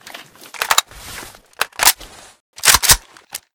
akm_reload_empty.ogg